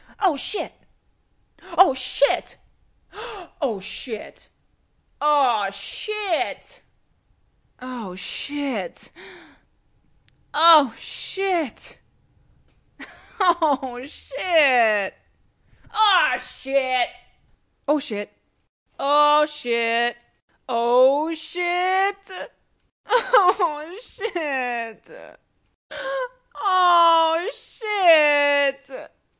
视频游戏中的女声 " 哦，该死
性感，愤怒，快乐，有趣，悲伤...... 使用USB Mic和Audacity录制。
Tag: 讲话 谈话 声音 女孩 性感 英语 女性 讲话 美国 声乐 女人 视频游戏